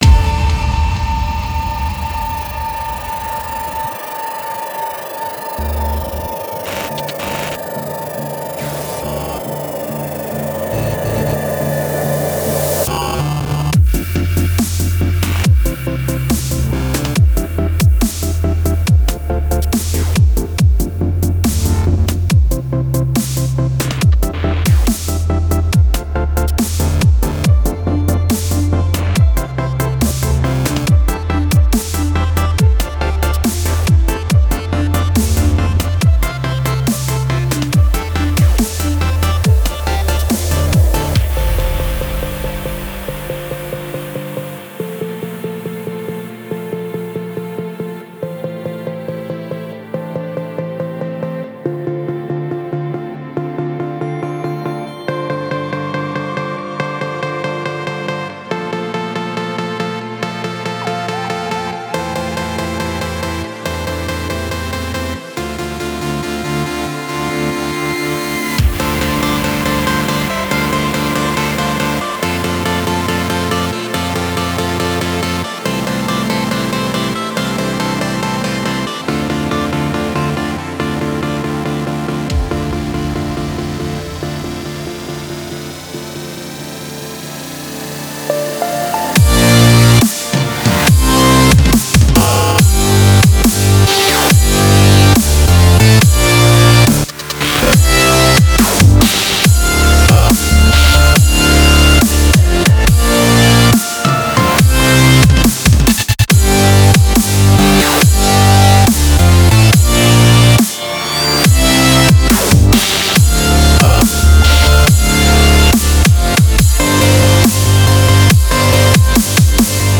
атмосферная электронная композиция